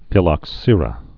(fĭlŏk-sîrə, fĭ-lŏksər-ə)